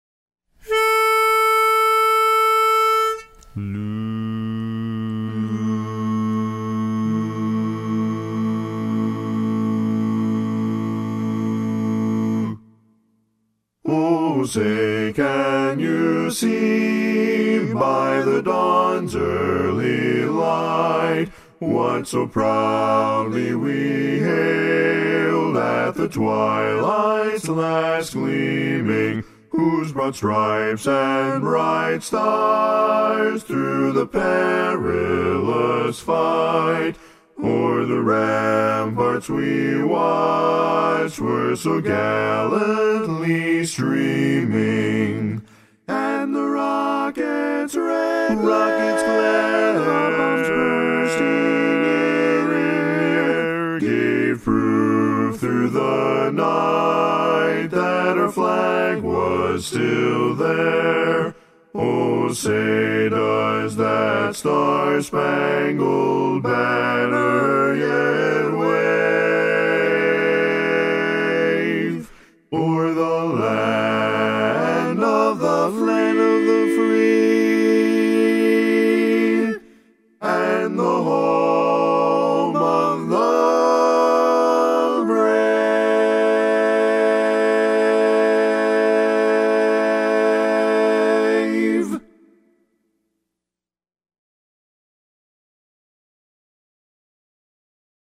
Up-tempo
Barbershop
A Major
Bass